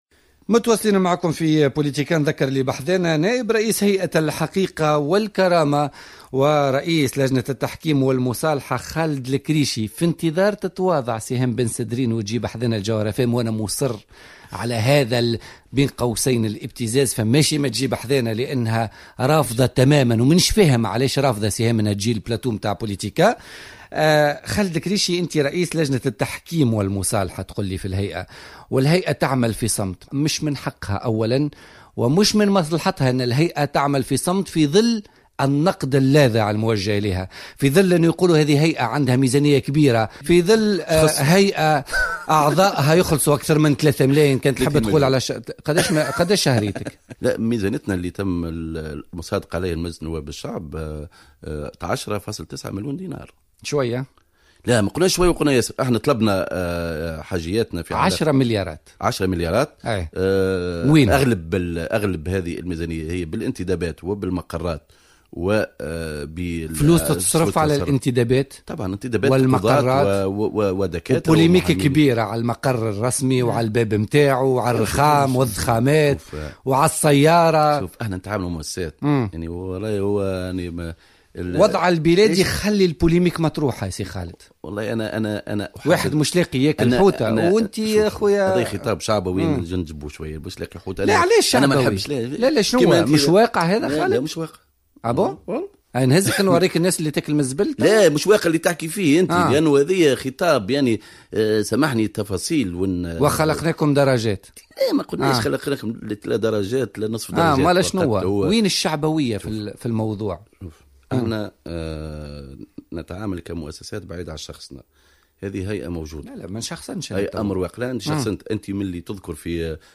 قال خالد الكريشي نائب رئيس هيئة الحقيقة والكرامة ورئيس لجنة التحكيم والمصالحة ضيف برنامج بوليتكا لليوم الاثنين 4 أفريل 2016 إن الدولة هي من يعطل اليوم مطالب التحكيم والمصالحة التي ترسلها الهيئة للمكلف بنزاعات الدولة للموافقة عليها.